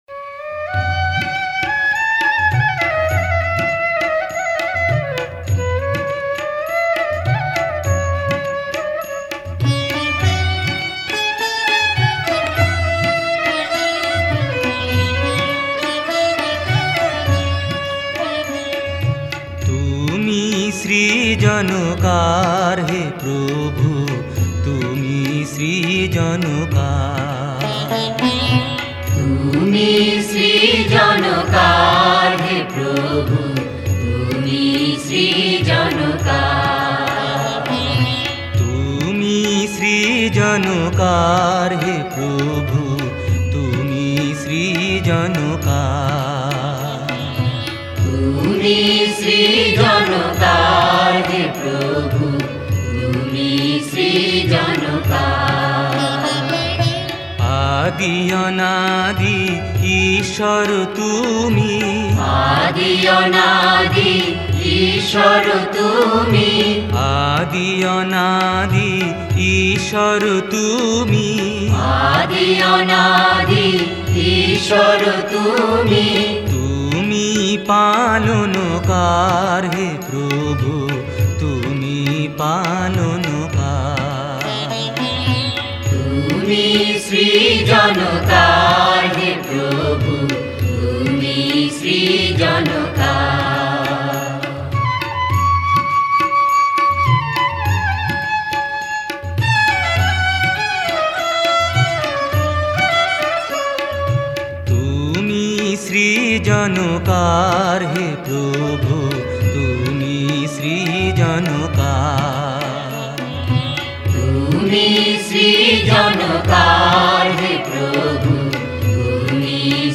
Directory Listing of mp3files/Bengali/Devotional Hymns/Bhajons/ (Bengali Archive)